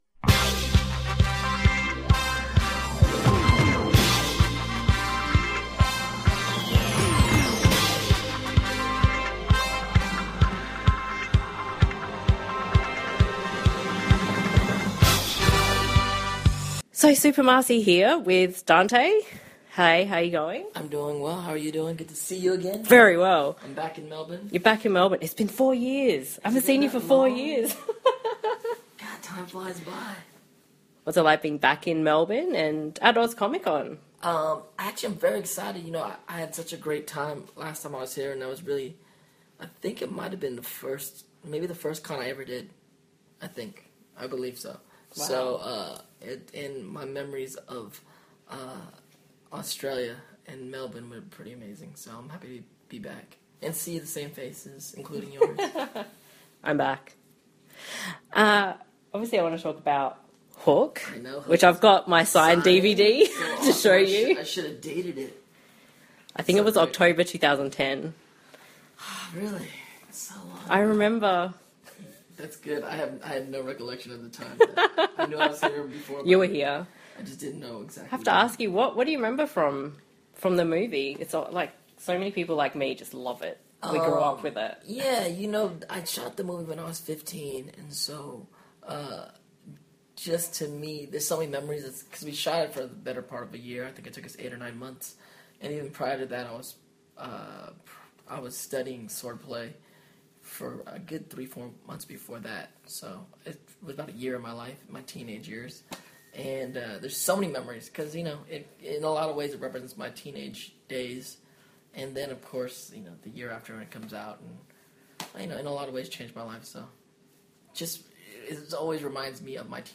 OZ Comic Con Melbourne 2014: Interview with Dante Basco
oz-comic-con-interview-with-dante-basco.mp3